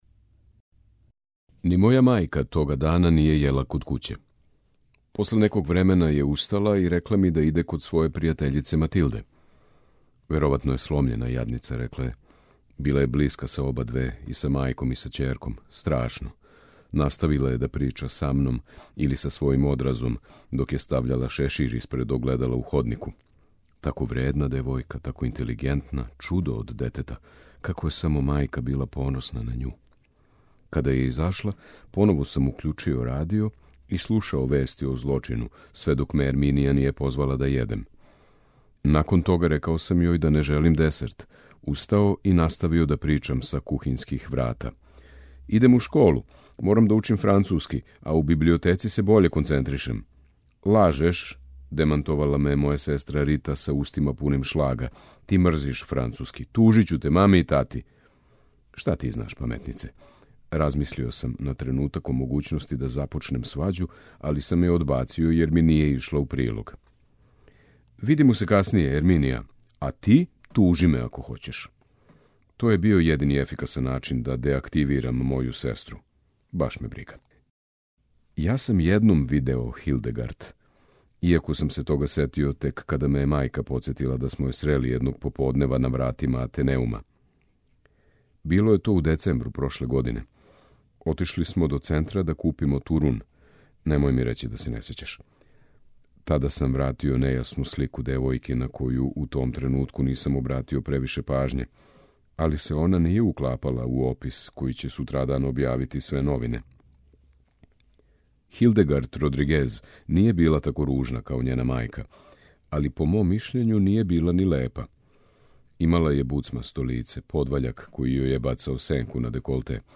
У емисији Путеви прозе, можете слушати делове романа савремене шпанске списатељице Алмудене Грандес „Франкенштајнова мајка”.
Књига за слушање